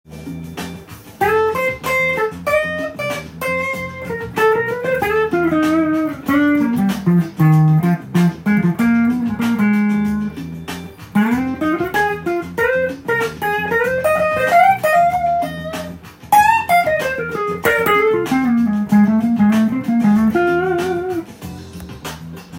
今回はF7 ワンコードのユーチューブのカラオケを使いました。
F7で使えるスケールはFmペンタトニックスケールや
自分なりのファンク系ギターソロを作っていきましょう♪
オリジナルのギターソロtab譜
f7.solo_.tab_.m4a